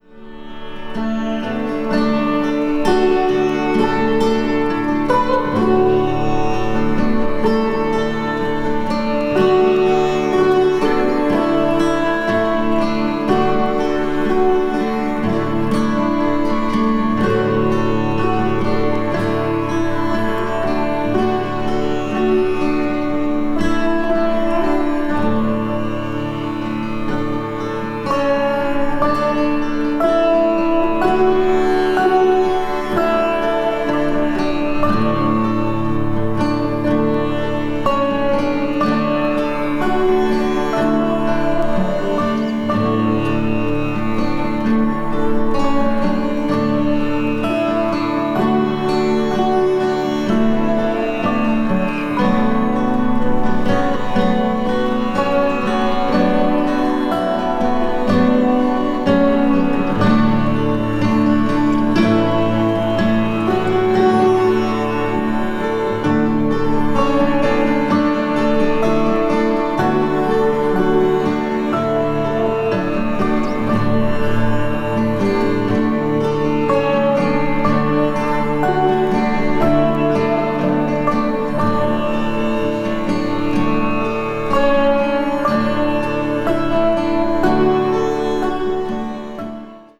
media : EX+/EX+(一部わずかにチリノイズが入る箇所あり)